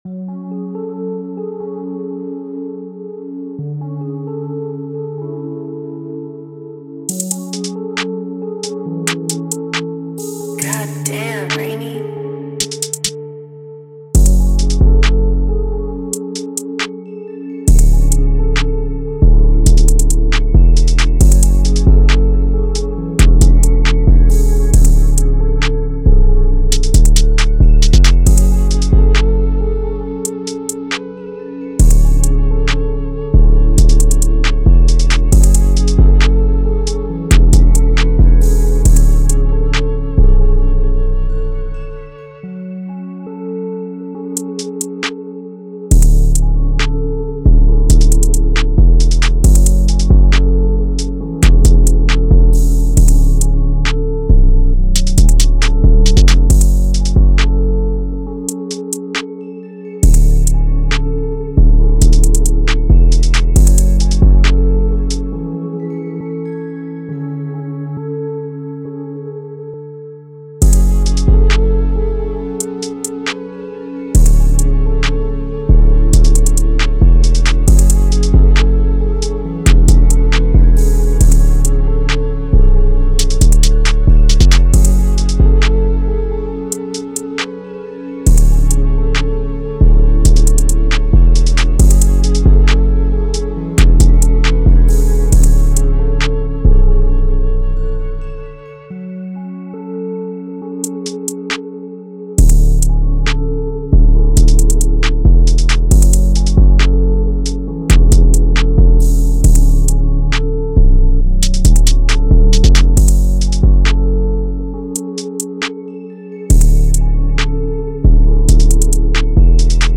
136BPM (RAP/ALTERNATIVE RAP)